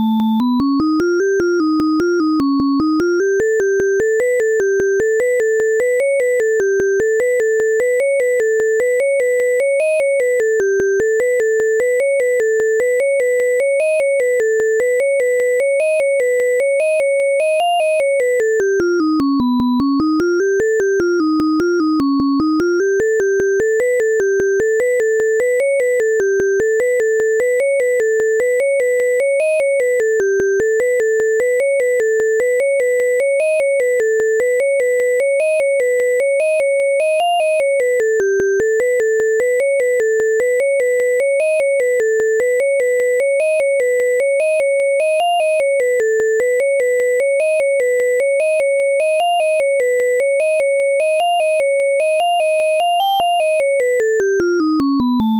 Suppose you go up an interval when you see a { and down one when you see a } (and, say, repeat the last note when you hit a comma)-- what would it sound like?
You feed it an integer or rational number (either between, or with numerators and denominators between -10 and 10, unless you modify it to take these safety locks off) and it produces an mp3 (all going well) of beeping noises.
It is not unmusical.
The bad additive synthesis is all my addition.